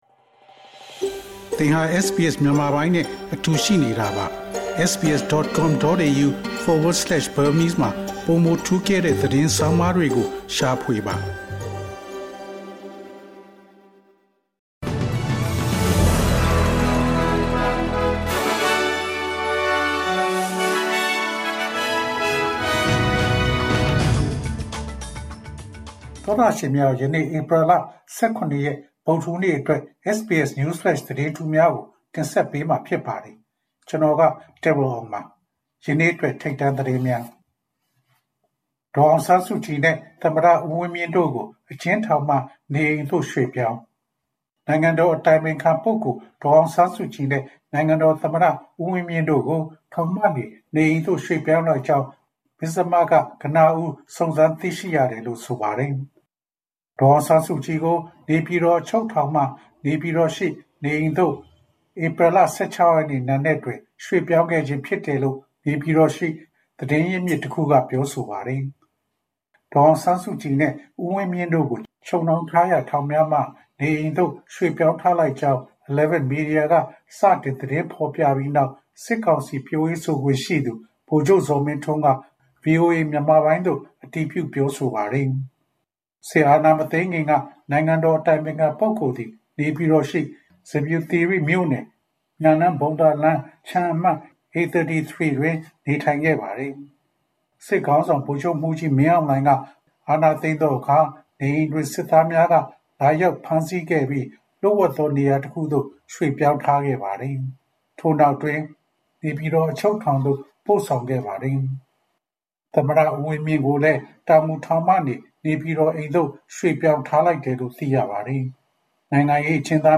ဧပြီလ ၁၇ ရက် တနင်္လာနေ့ SBS Burmese News Flash သတင်းများ။ Source: SBS / SBS Burmese